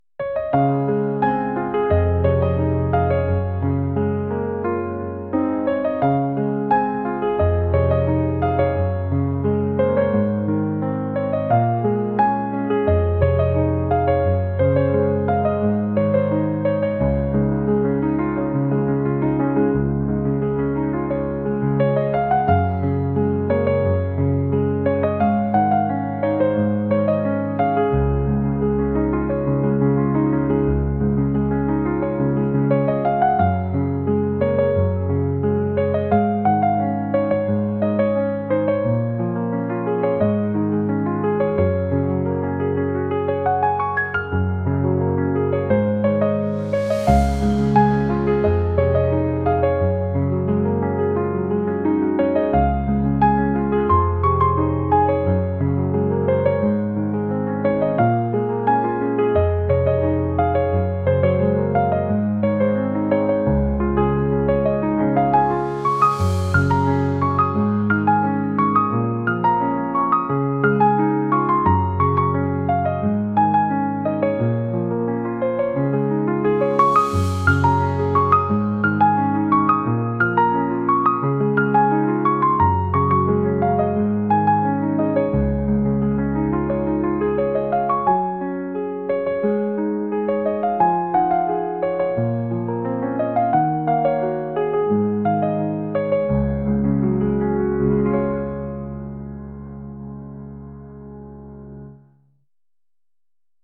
春を迎えたかのようなピアノ曲です 音楽素材（MP3）ファイルのダウンロード、ご利用の前に必ず下記項目をご確認ください。